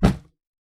Punching Box Intense C.wav